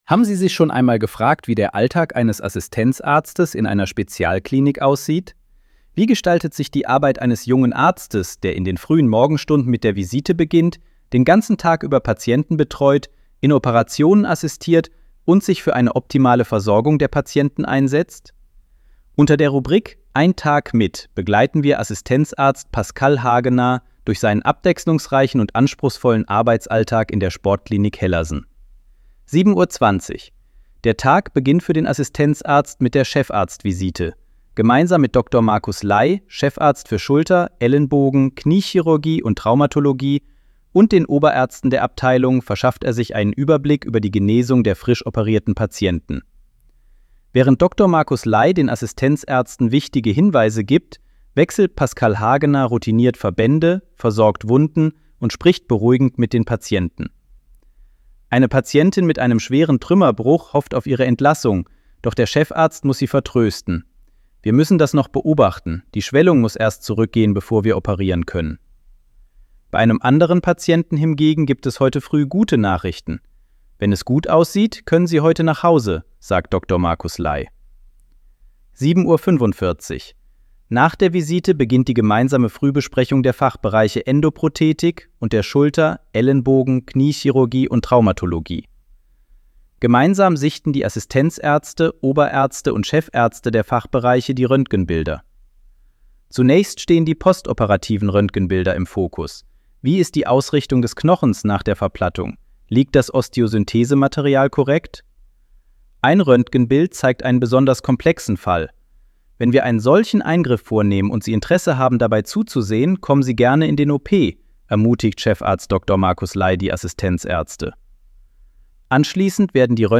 Artikel vorlesen lassen ▶ Audio abspielen Haben Sie sich schon einmal gefragt, wie der Alltag eines Assistenzarztes in einer Spezialklinik aussieht?